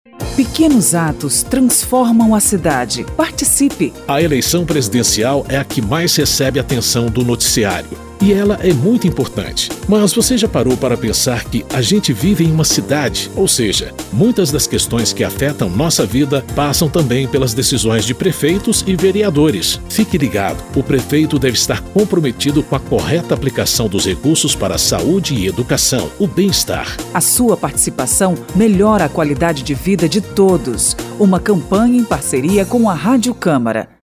São 7 spots de 30 segundos sobre saúde, transporte, educação e segurança, destacando o papel de cada um – prefeito, vereadores e cidadãos – na melhoria da vida de todos.
spot-pequenos-atos-7.mp3